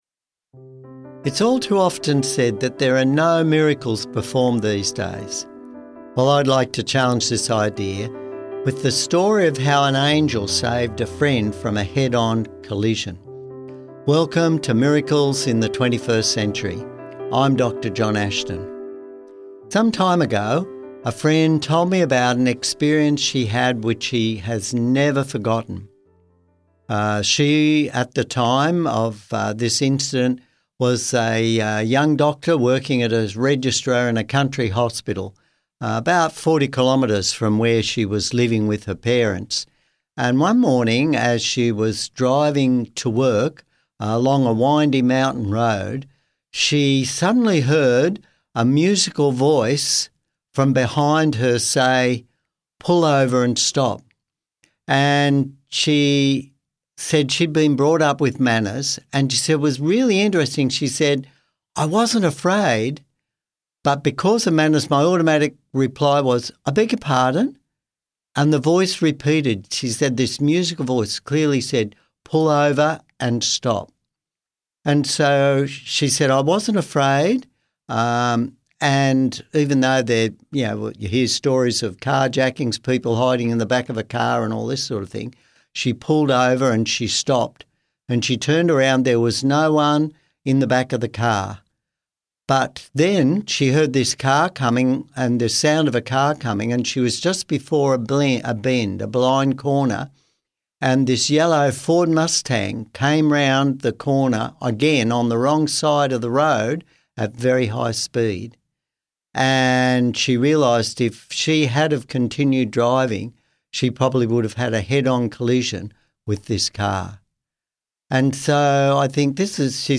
Real angel stories and divine intervention prove that miracles still happen today.
Music Credits: